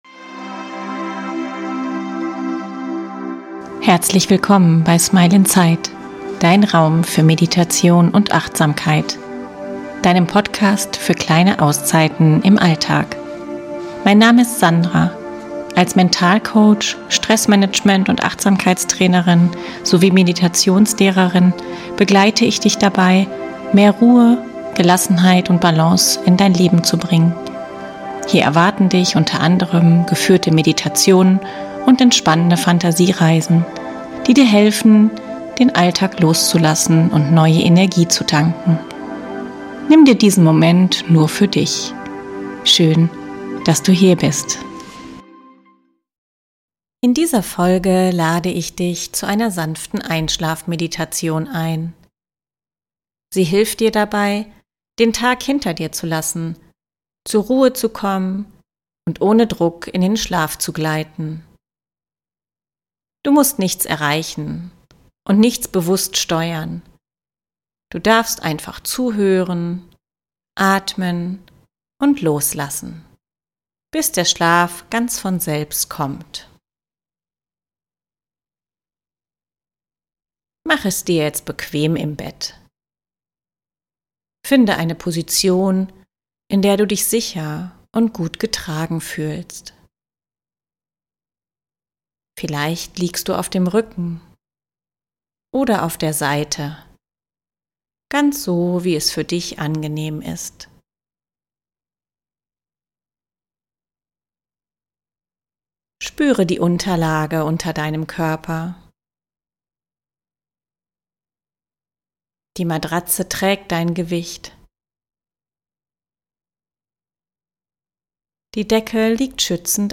Einschlafmeditation - Sanft in die Nacht gleiten ~ Smile inside - Dein Raum für Meditation und Achtsamkeit Podcast